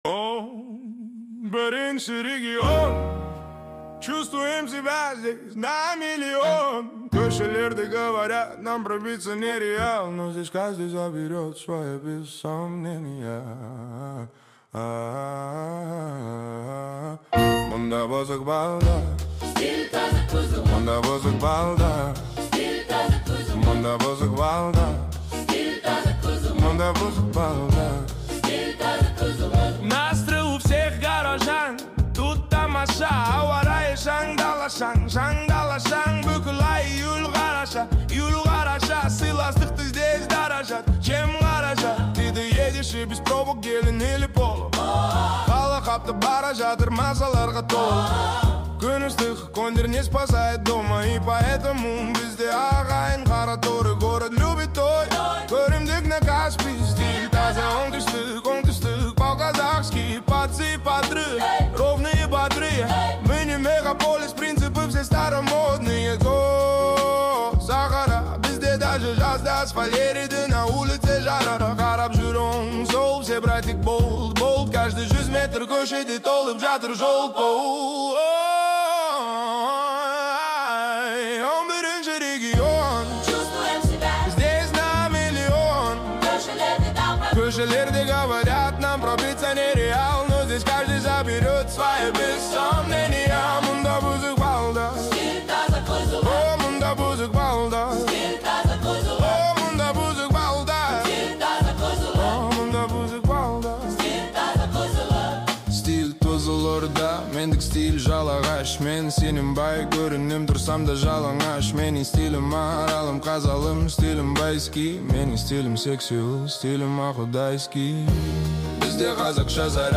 (AI Cover) 1950's Jazz Soul Version